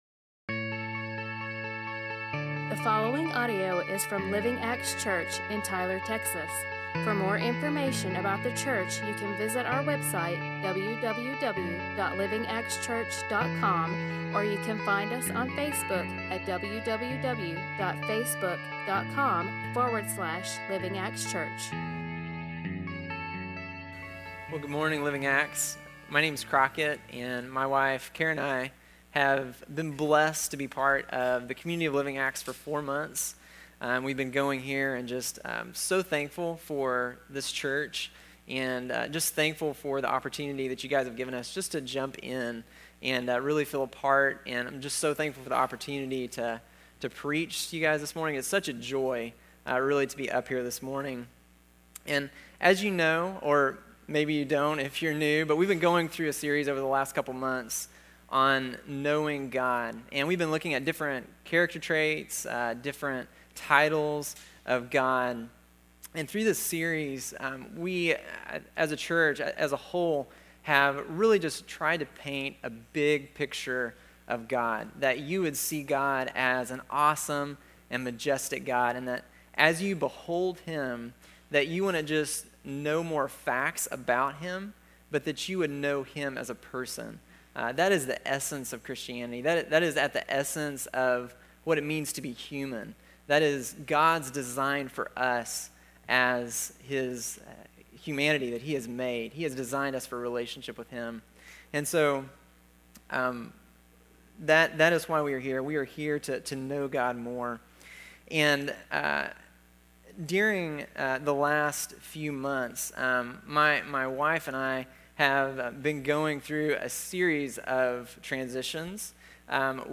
A message from the series "Knowing Our God."